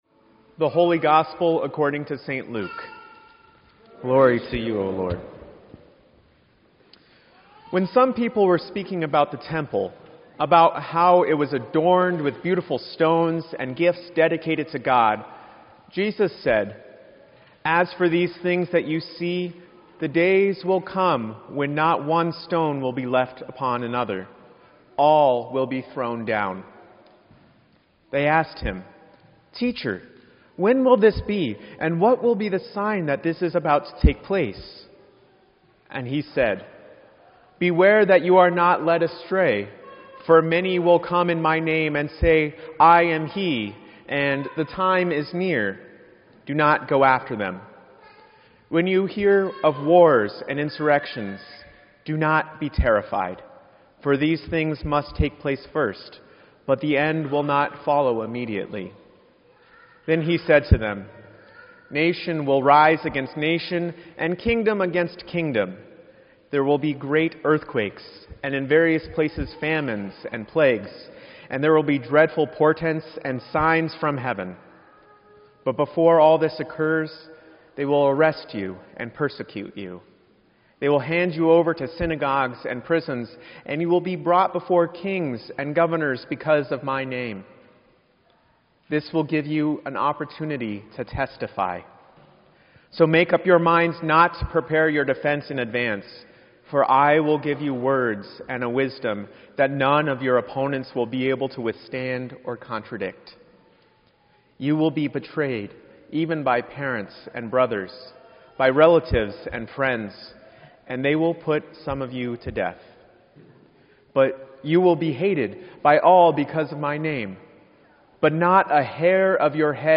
Twenty-sixth Sunday After Pentecost